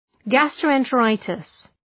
Shkrimi fonetik{,gæstrəʋ,entə’raıtıs}